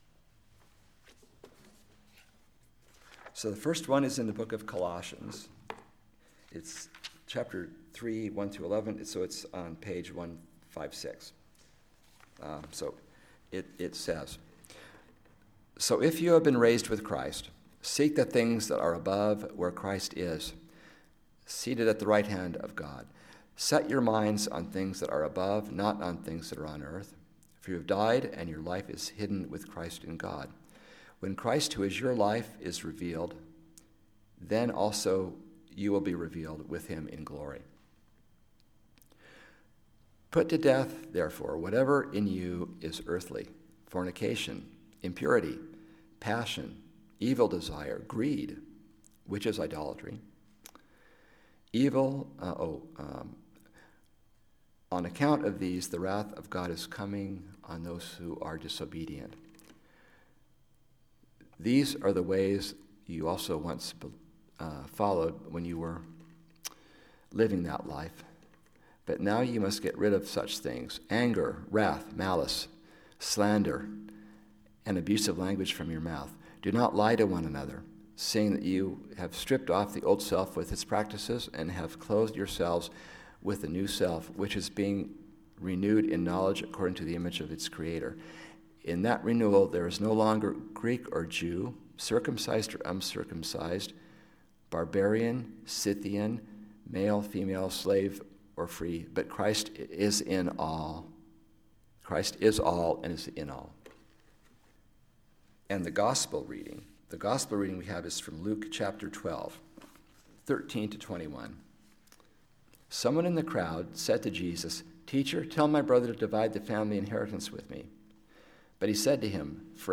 Listen to the most recent message from Sunday worship at Berkeley Friends Church, “The New Self”.